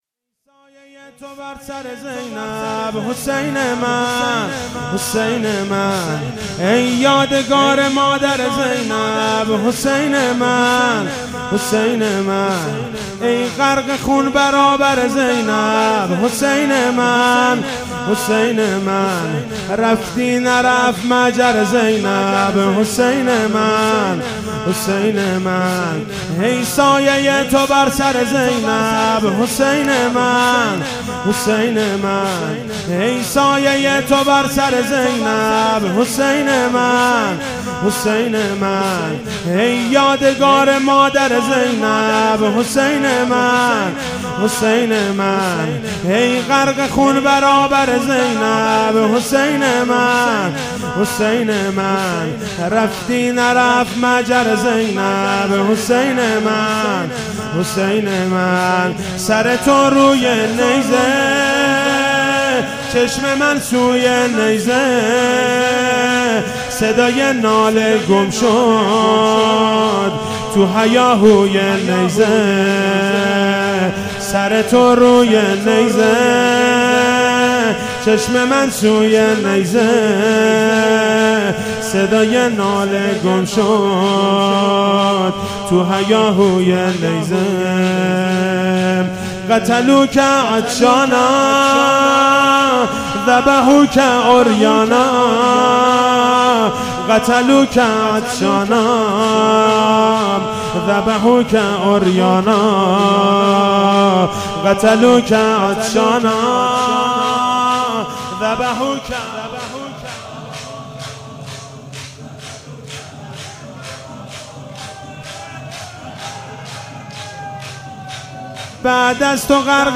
فاطمیه